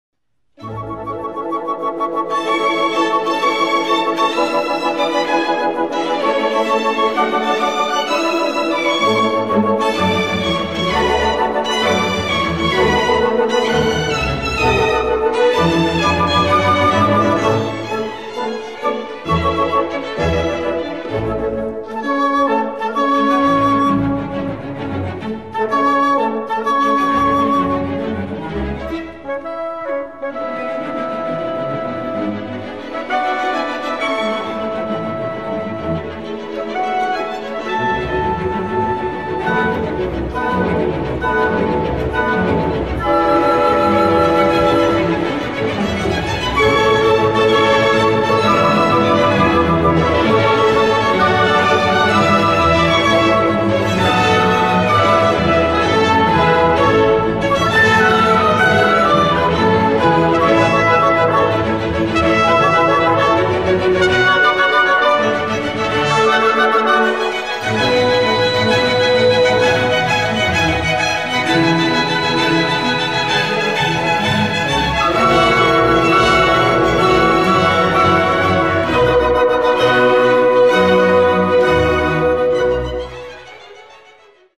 Sinfonía No.4 “Italiana”Felix Mendelssohn